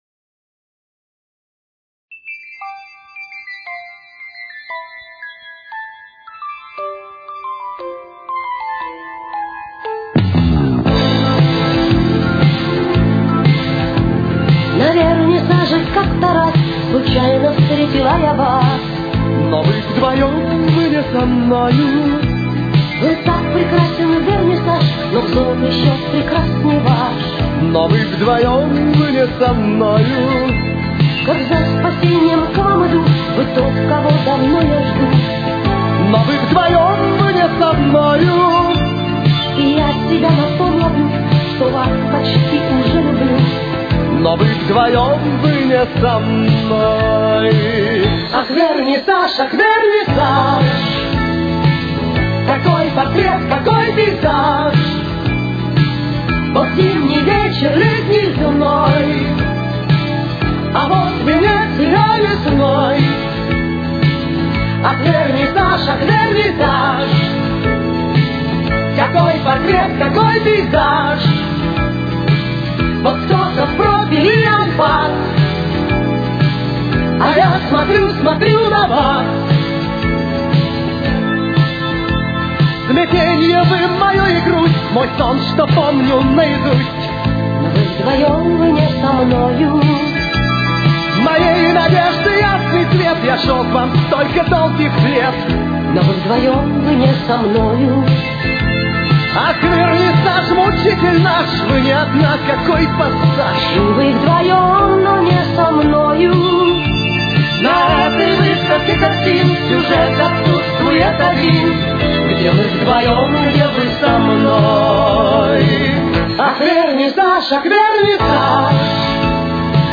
с очень низким качеством (16 – 32 кБит/с)
Темп: 119.